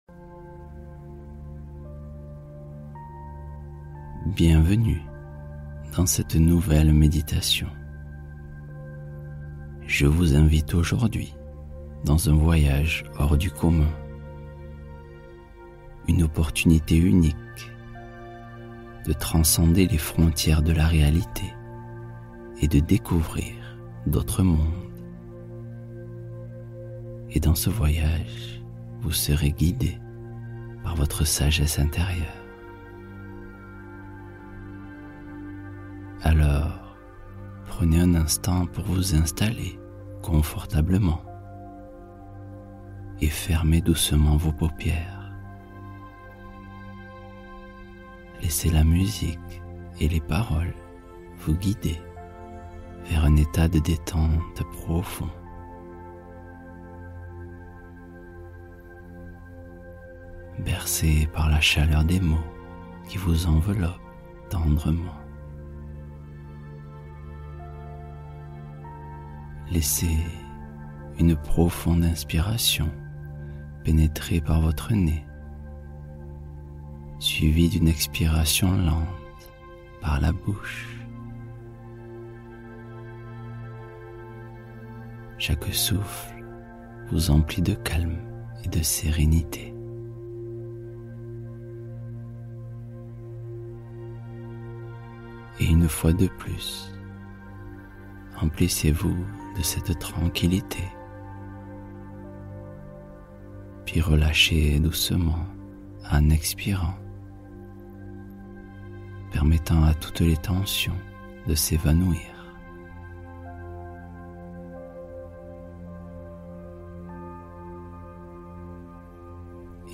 Exploration Mentale : Visualisation guidée pour élargir sa perception de soi